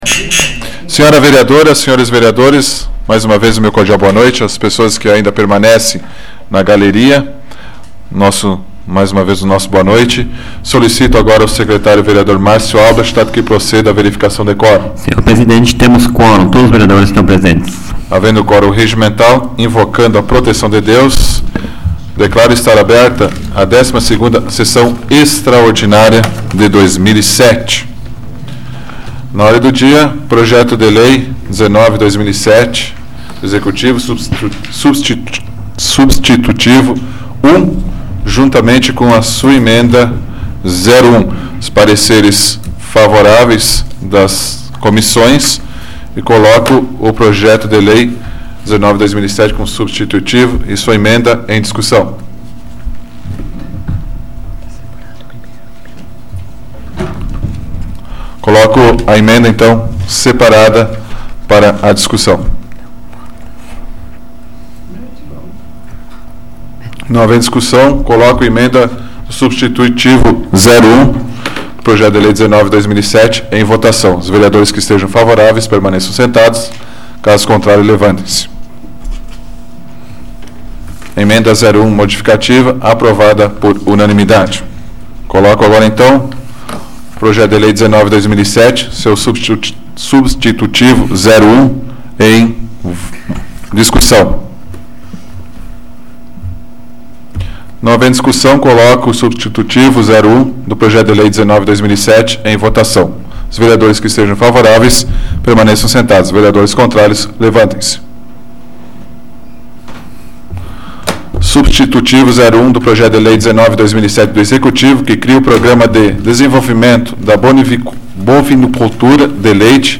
Áudio da 42ª Sessão Plenária Extraordinária da 12ª Legislatura, de 10 de setembro de 2007